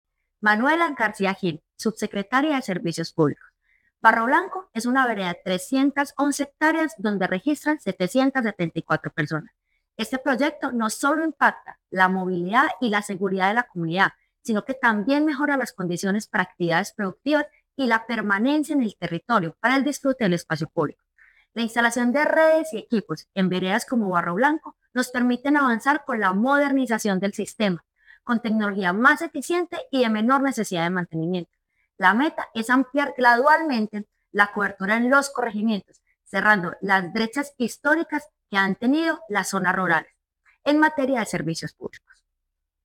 Declaraciones de subsecretaria de Servicios Públicos, Manuela García Gil.